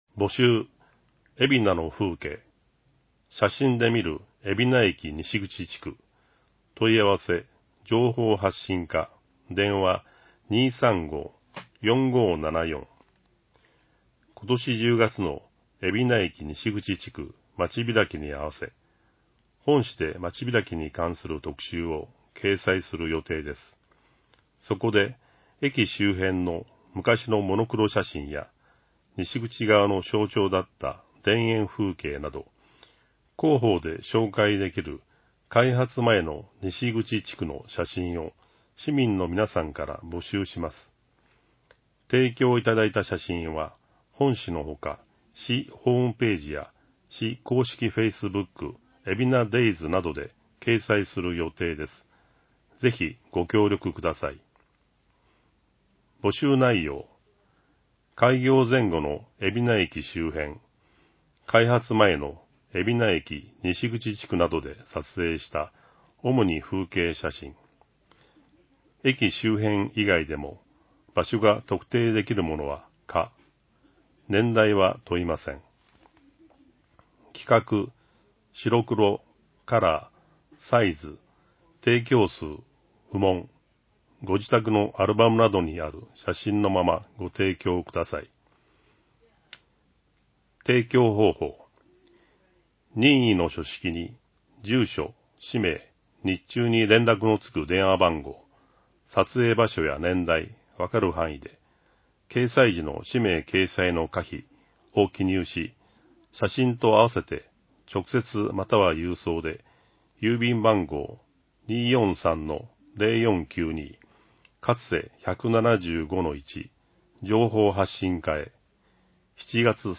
広報えびな 平成27年7月1日号（電子ブック） （外部リンク） PDF・音声版 ※音声版は、音声訳ボランティア「矢ぐるまの会」の協力により、同会が視覚障がい者の方のために作成したものを登載しています。